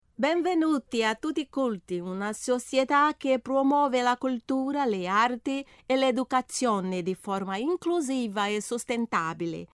Feminino